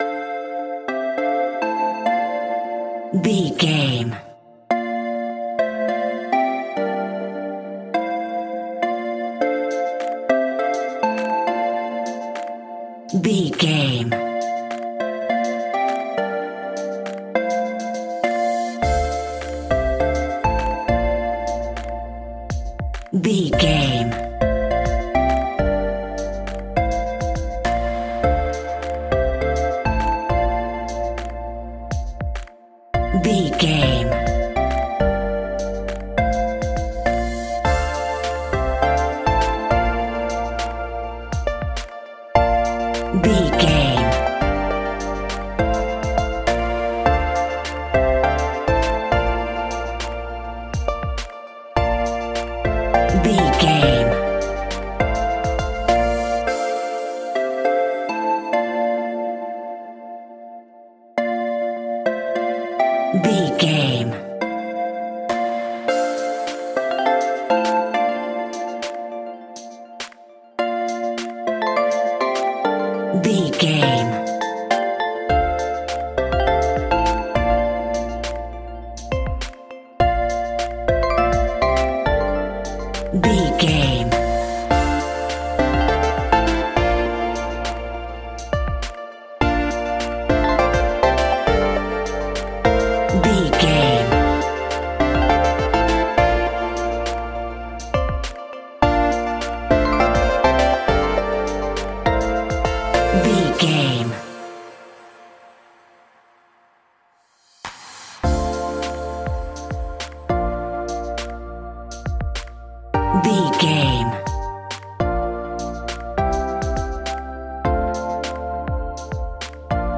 Aeolian/Minor
hip hop
instrumentals
chilled
laid back
hip hop drums
hip hop synths
piano
hip hop pads